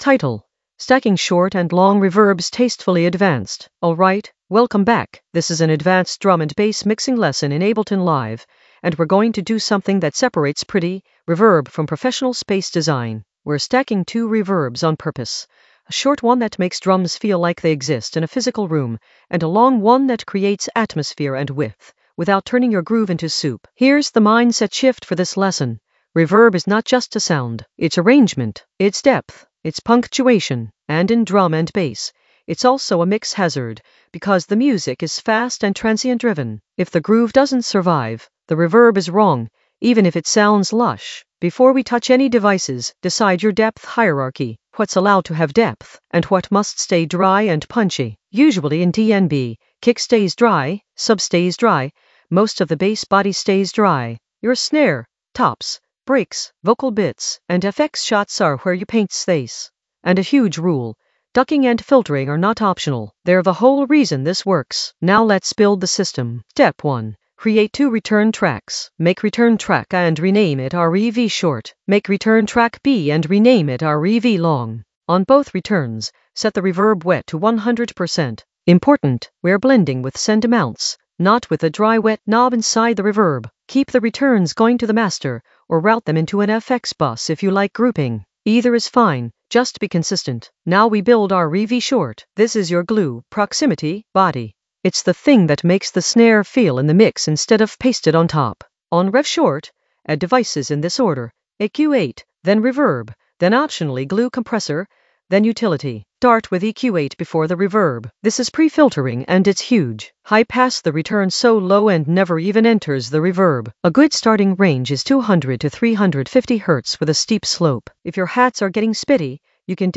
Narrated lesson audio
The voice track includes the tutorial plus extra teacher commentary.
An AI-generated advanced Ableton lesson focused on Stacking short and long reverbs tastefully in the FX area of drum and bass production.